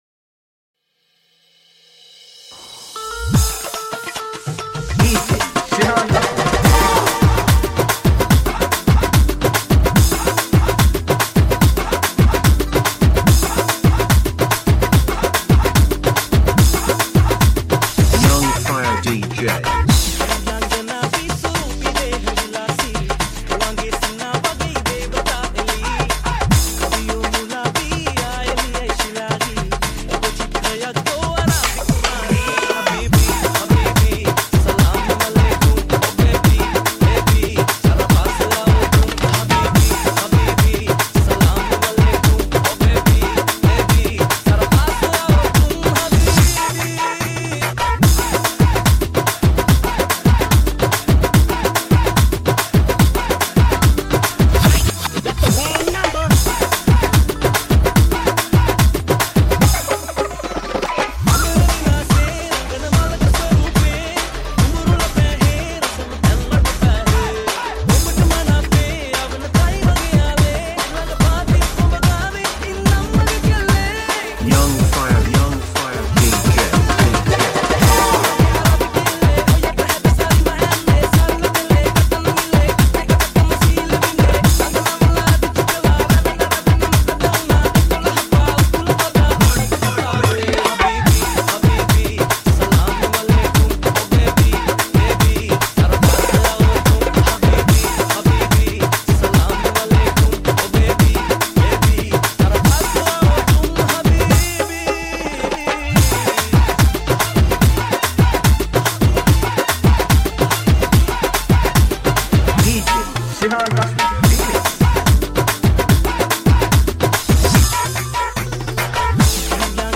DJ Nonstop